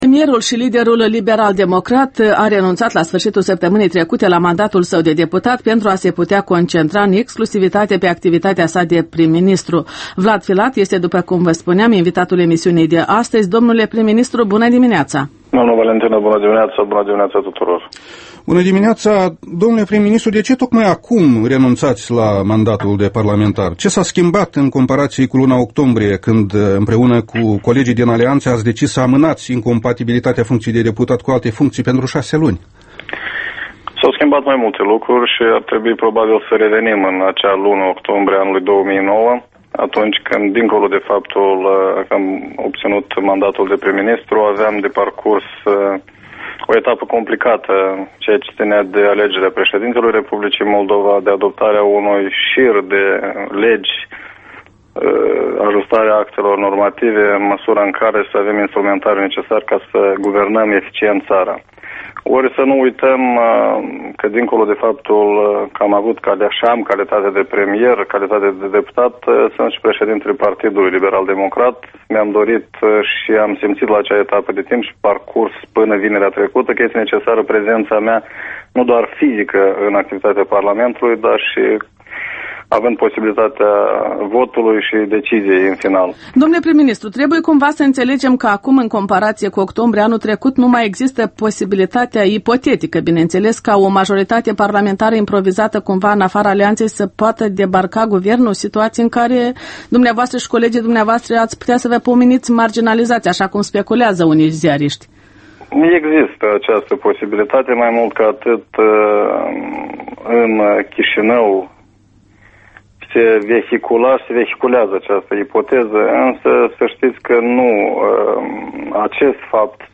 Interviul matinal EL: cu premierul Vlad Filat